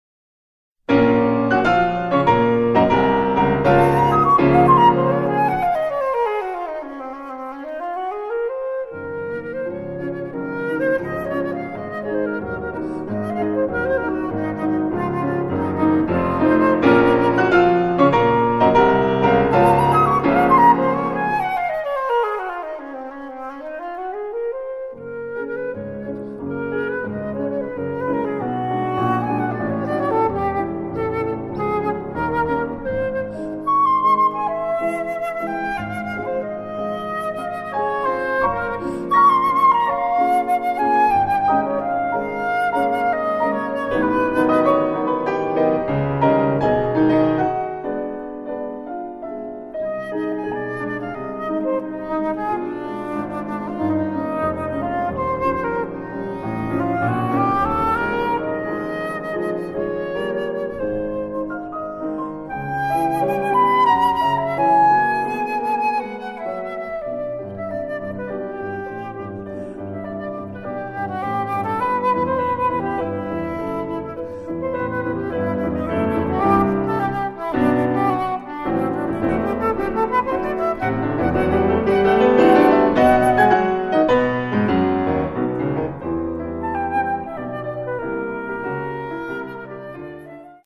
für Flöte und Klavier
Allegro con anima (Audio)